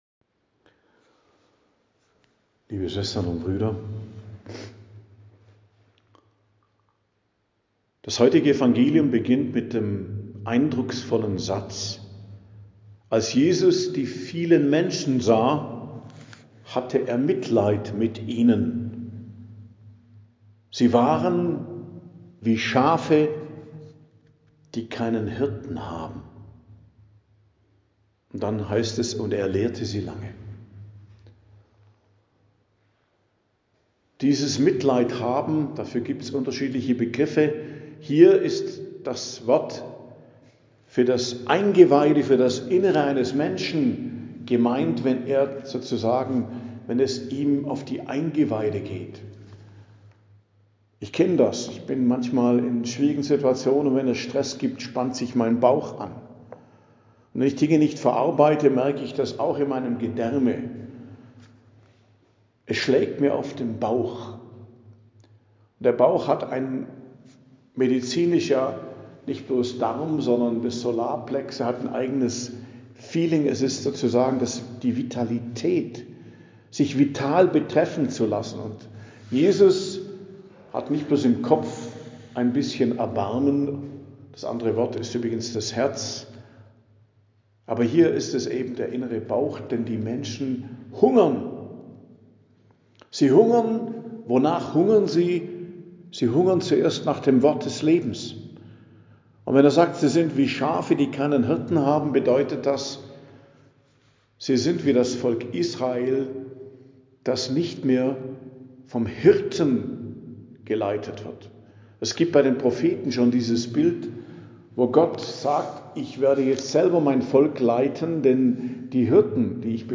Predigt am Donnerstag der 2.Woche der Weihnachtszeit, 8.01.2026